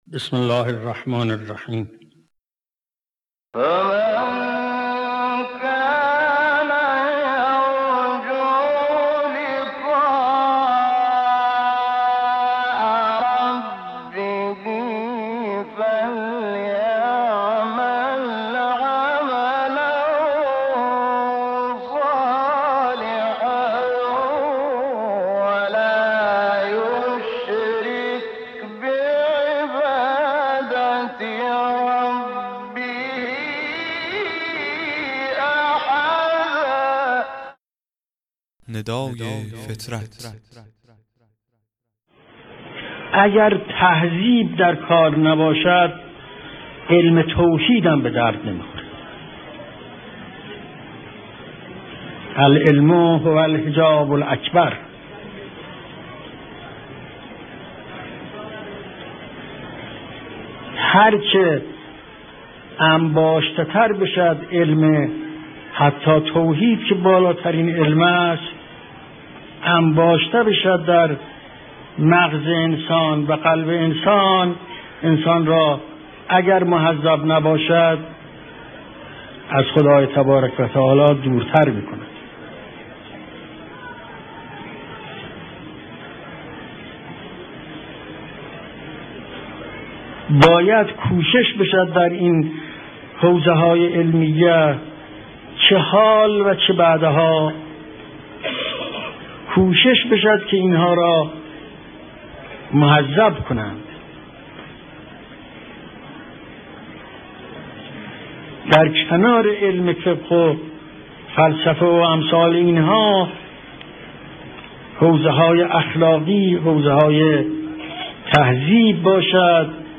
قطعه صوتی کوتاه و زیبا از امام خمینی (ره) در خصوص لزوم تزکیه نفس